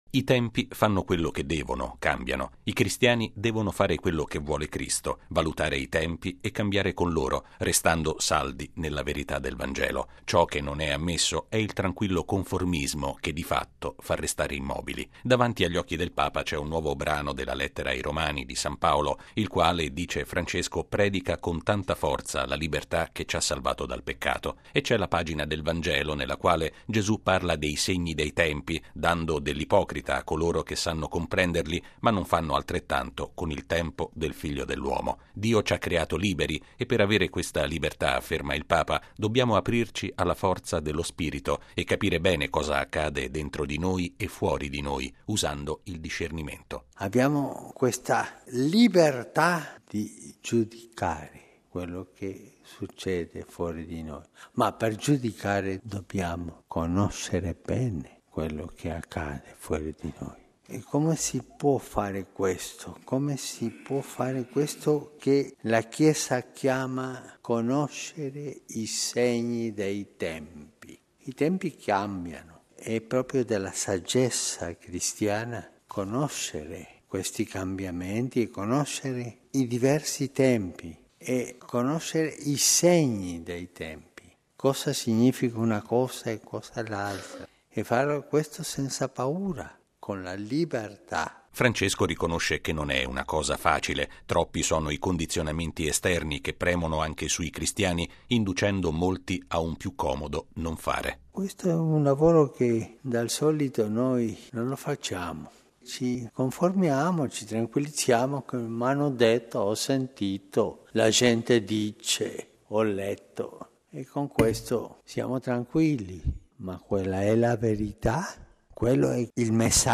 Lo ha affermato il Papa all’omelia della Messa del mattino, celebrata in Casa Santa Marta.